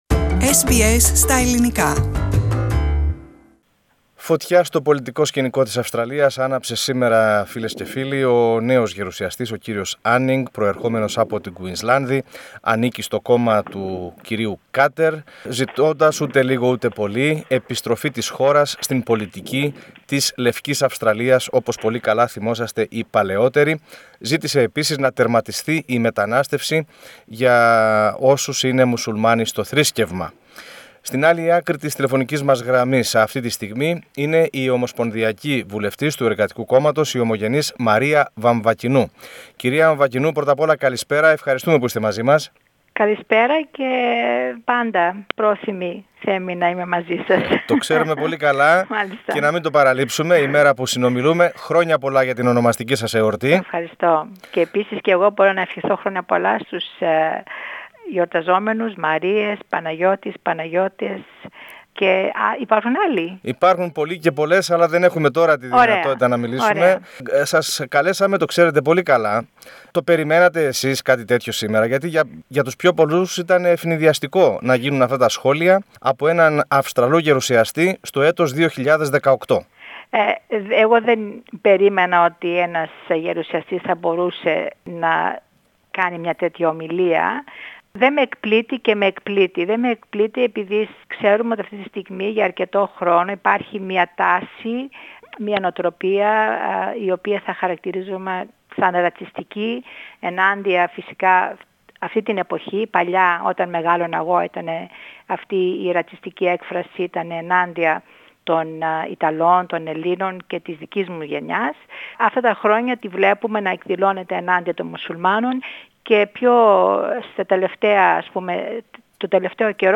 Η βουλευτής του Εργατικού Κόμματος ανέφερε πως ρατσισμός υπήρχε όταν μεγάλωνε και η ίδια. Περισσότερα στη συνέντευξη που παραχώρησε στο πρόγραμμά μας.